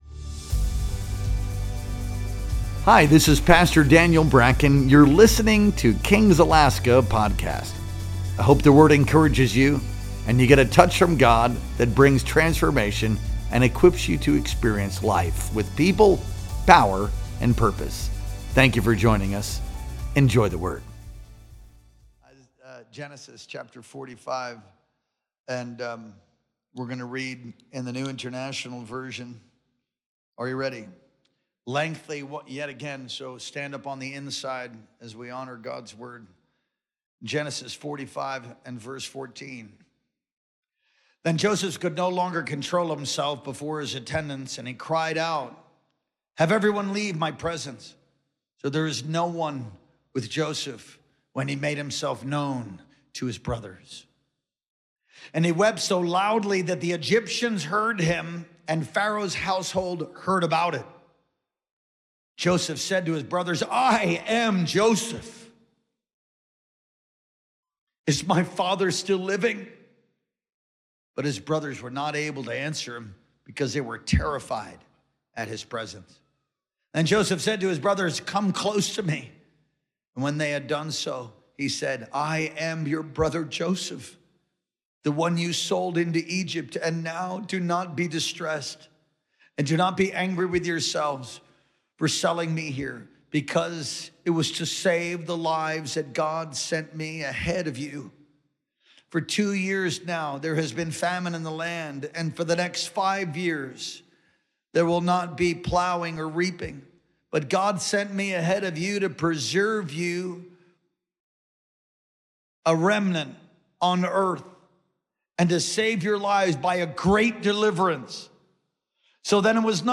Our Sunday Night Worship Experience streamed live on June 15th, 2025.